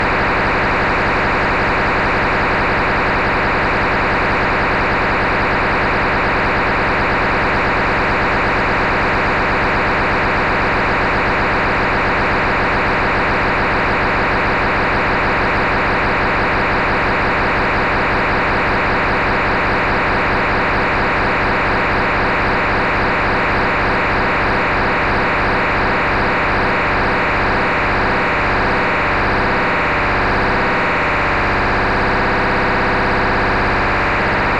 Цифровой сигнал на 423.660 МГц
Неизвестный Цифровой сигнал 423660 кГц или поток записанный в RAW IQ